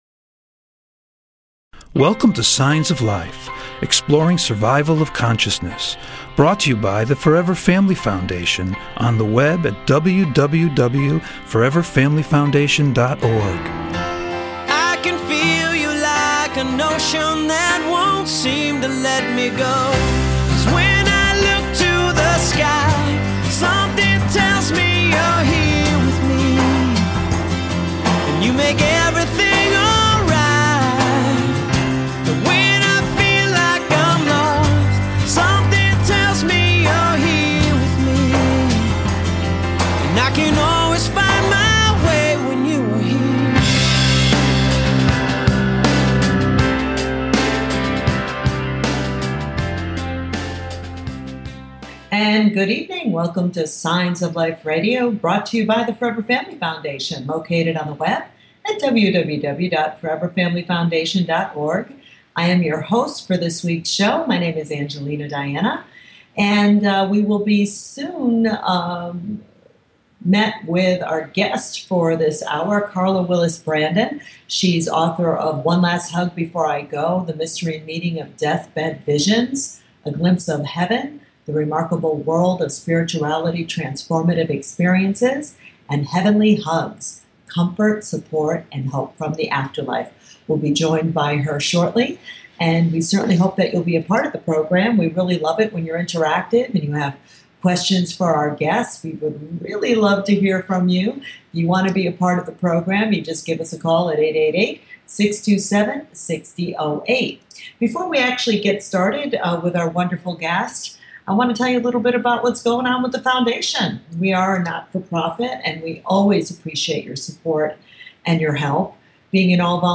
Signs of Life Radio Show is a unique radio show dedicated to the exploration of Life After Death!
Call In or just listen to top Scientists, Mediums, and Researchers discuss their personal work in the field and answer your most perplexing questions.